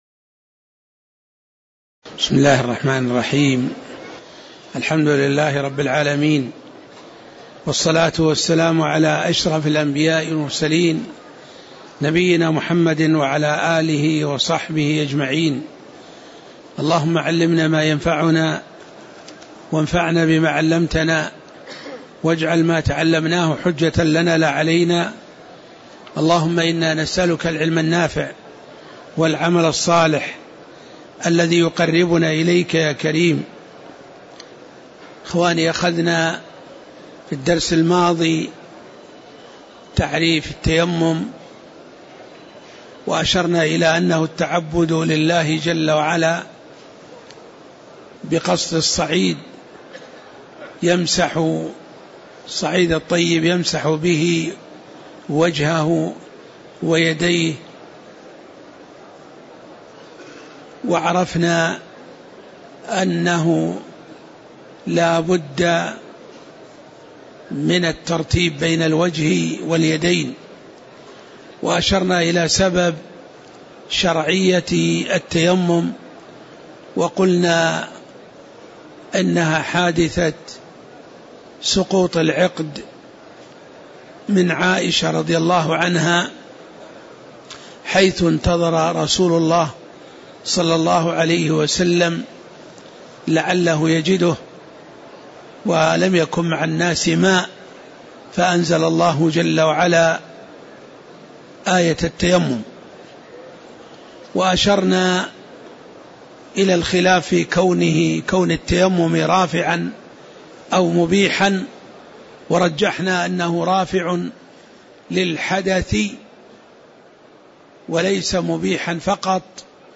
تاريخ النشر ٤ جمادى الآخرة ١٤٣٧ هـ المكان: المسجد النبوي الشيخ